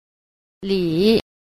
c. 理 – lǐ – lý